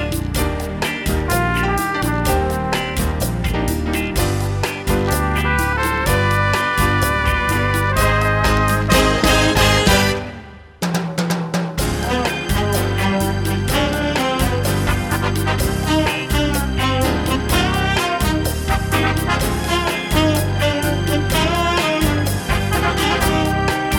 Jazz / Swing